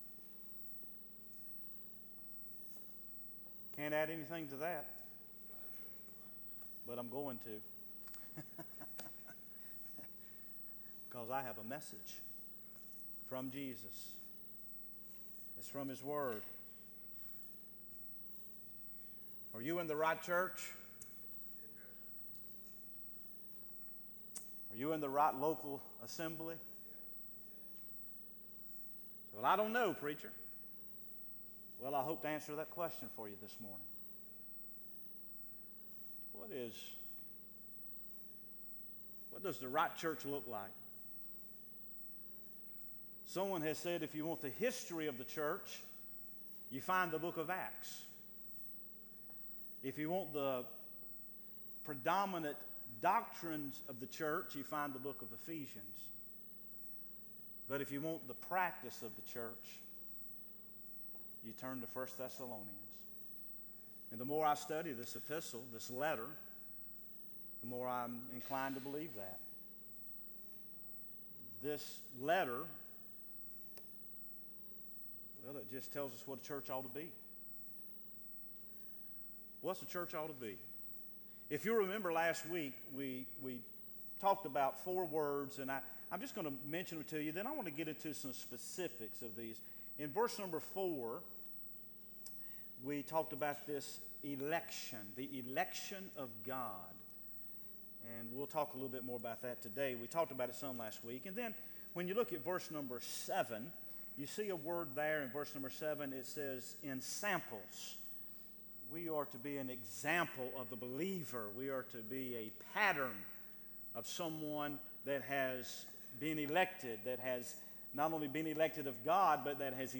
Sermons Archive • Page 3 of 166 • Fellowship Baptist Church - Madison, Virginia